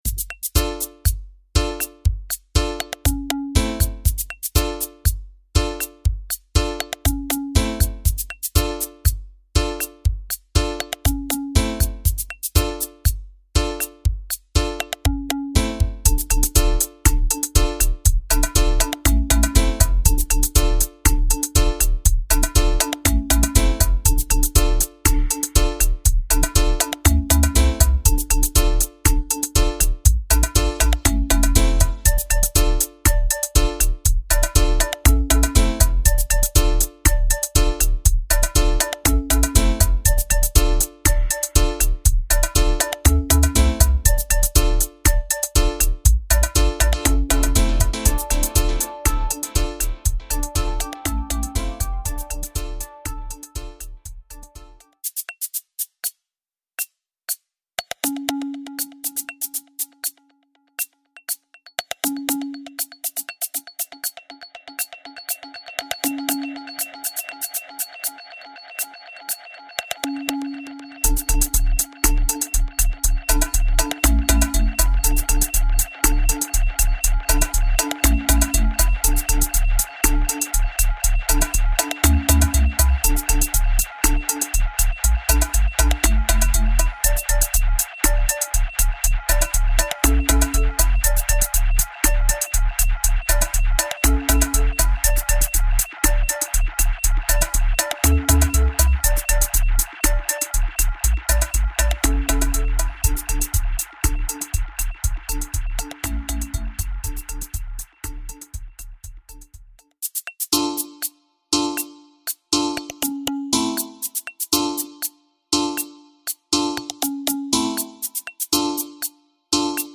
Promomix